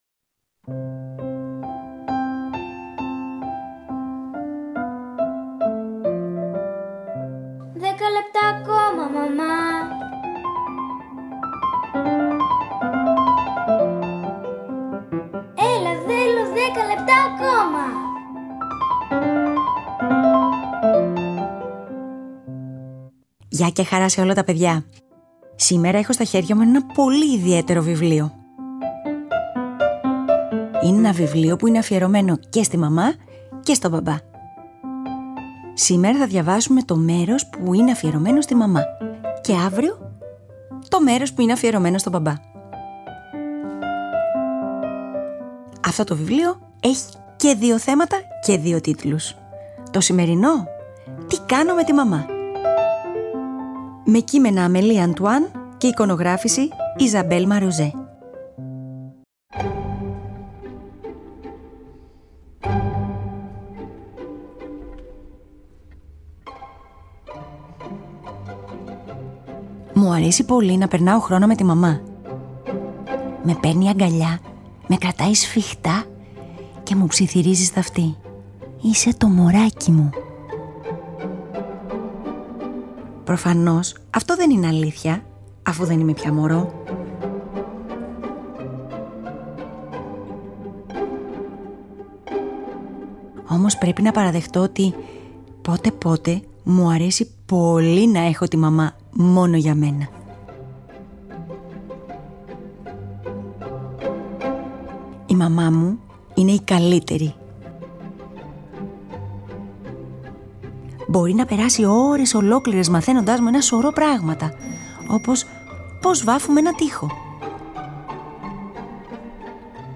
Σήμερα διαβάζουμε για τη μαμά!